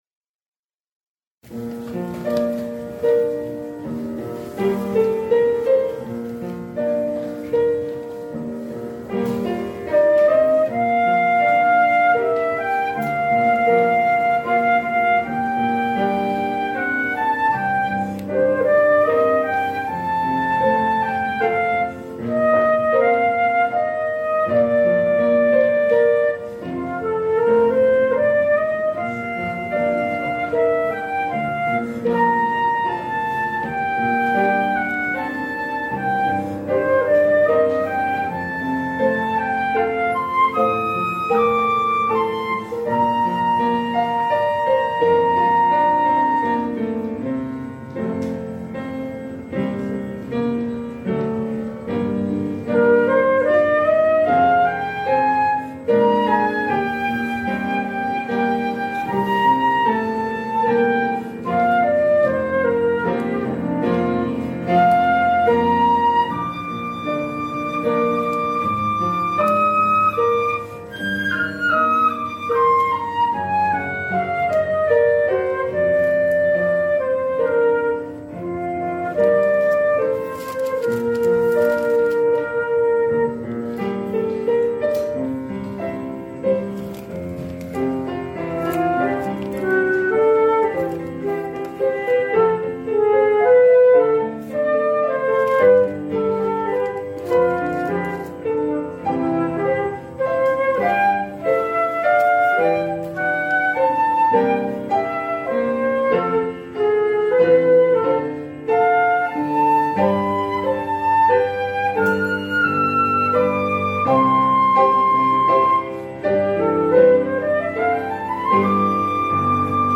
플룻 연주